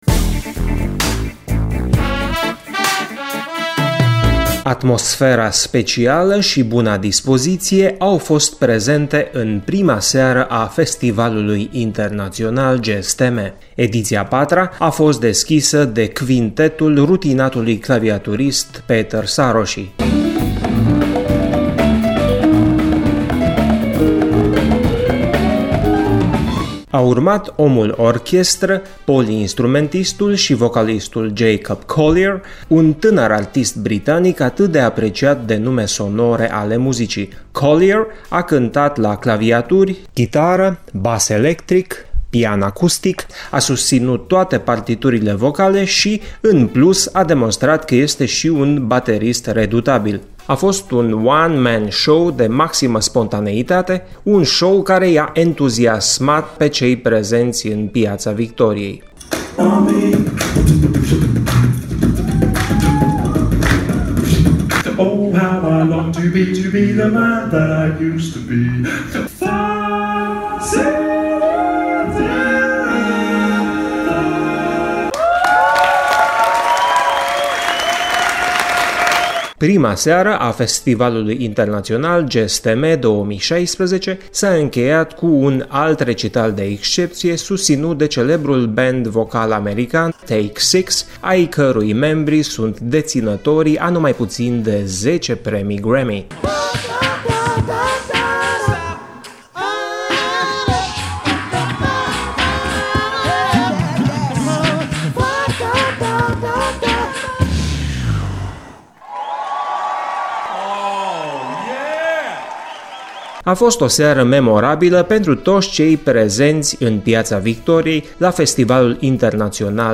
Concertele din prima seară au avut loc doar în Piața Victoriei, începând de astăzi vor fi ‘funcționale’ și scenele din Parcul Castelului (în spatele Muzeului Banatului) și din Parcul Civic (în spatele Hotelului Continental).